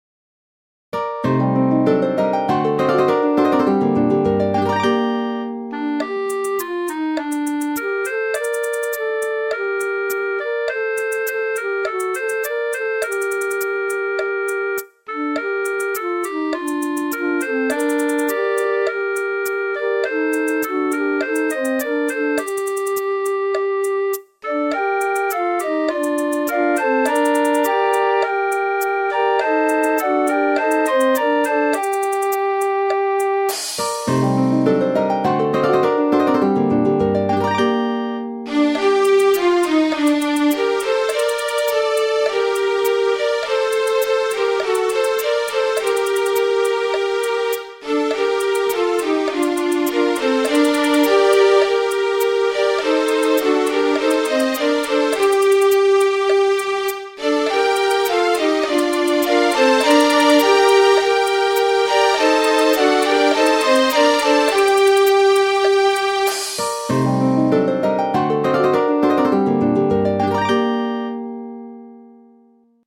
Фонограма-мінус (mp3)